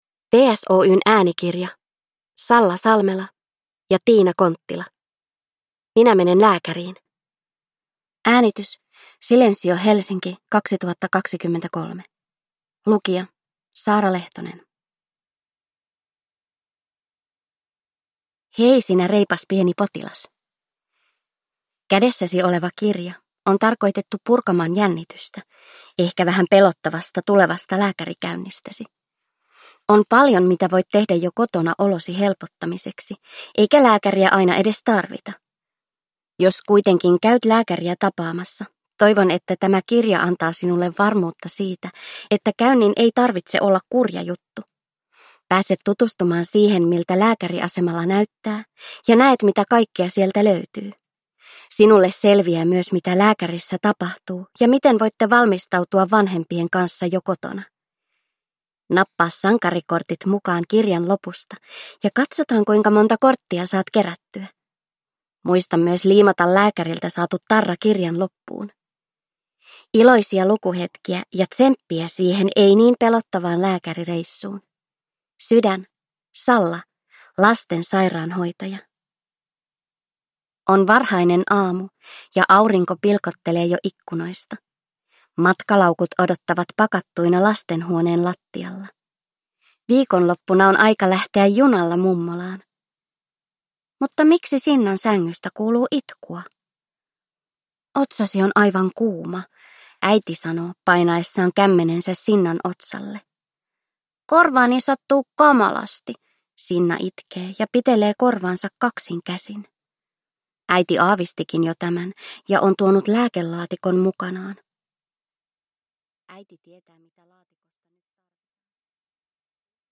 Minä menen lääkäriin – Ljudbok – Laddas ner